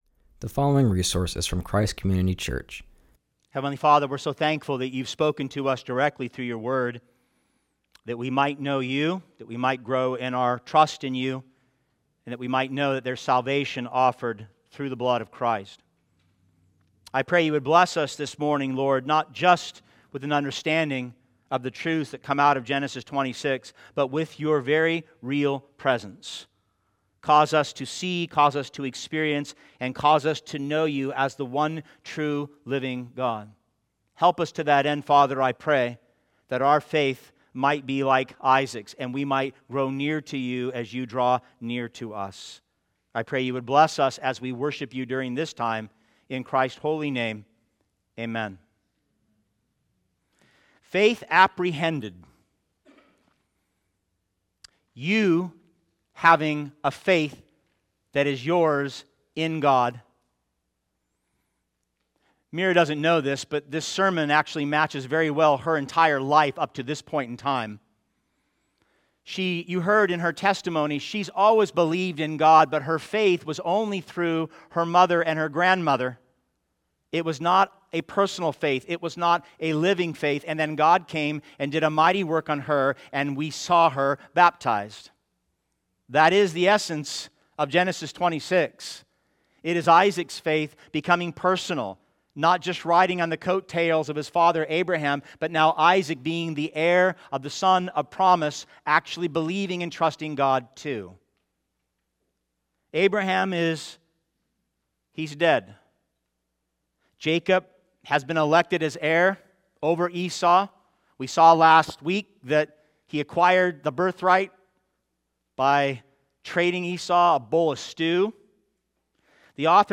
preaches from Genesis 26.